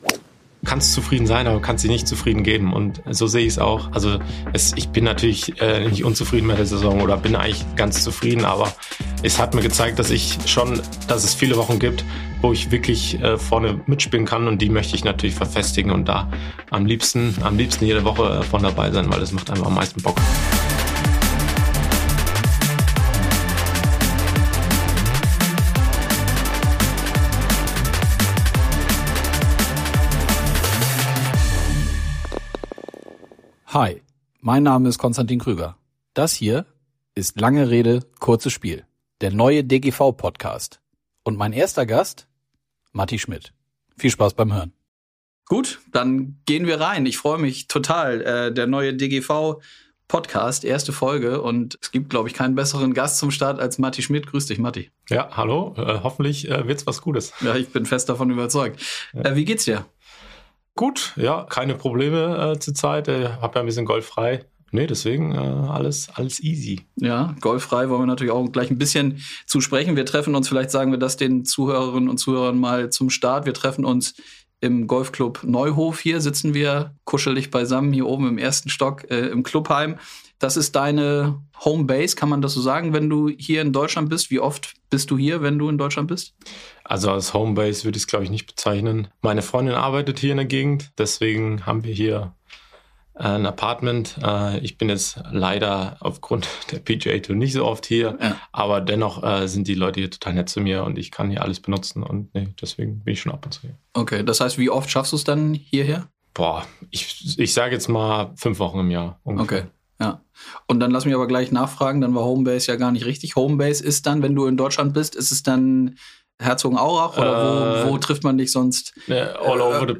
Gut gelaunt und mit der nötigen Ruhe, die ihn auf dem Golfplatz grundsätzlich auszeichnet.